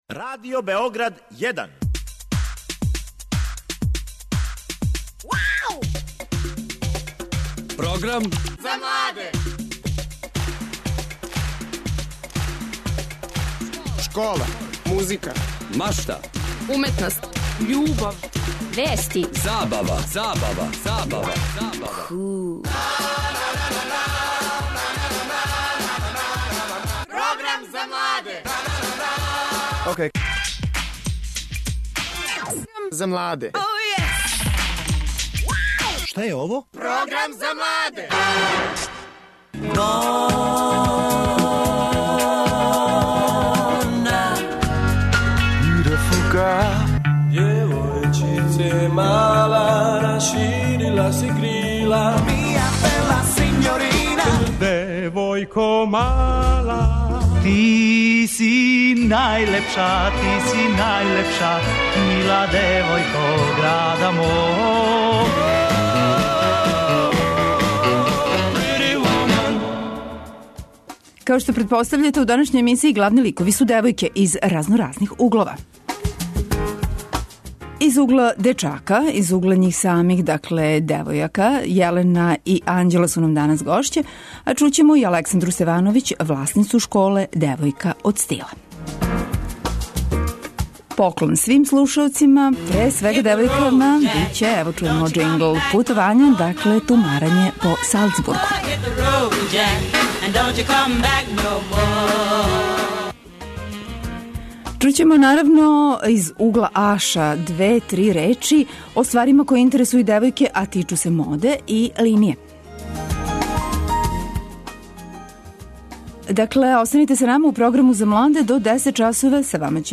Гости у студију су нам младе девојке